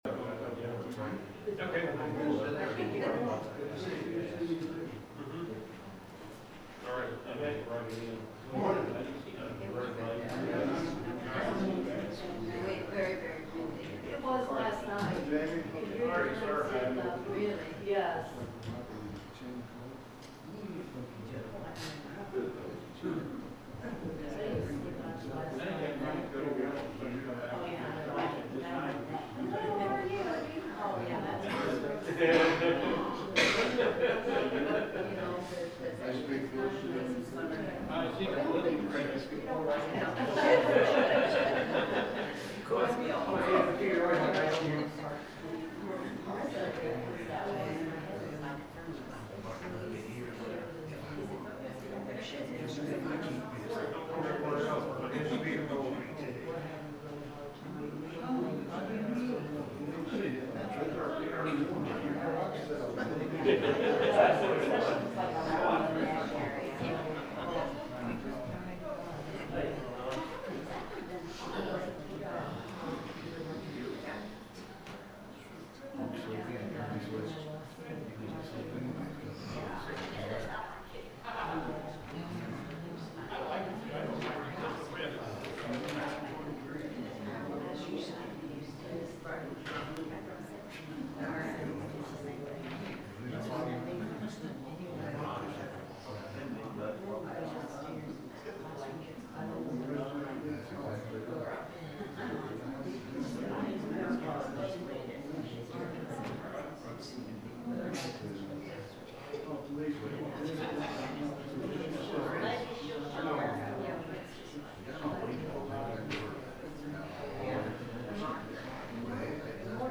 The sermon is from our live stream on 11/9/2025